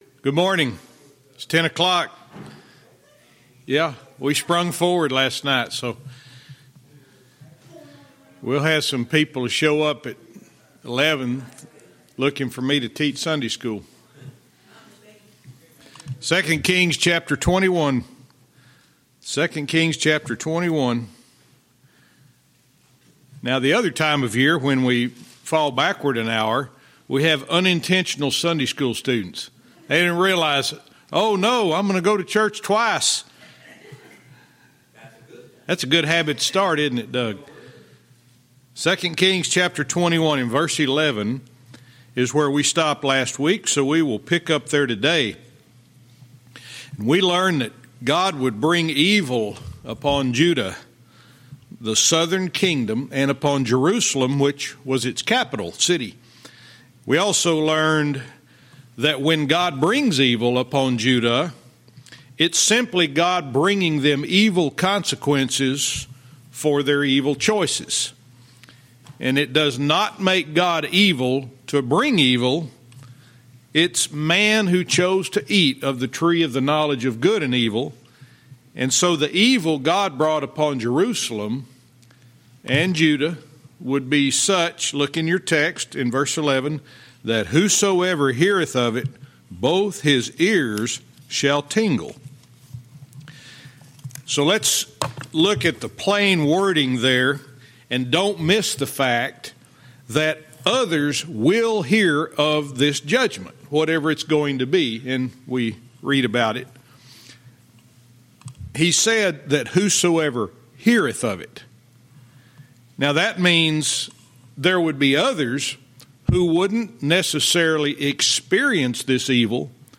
Verse by verse teaching - 2 Kings 21:11-14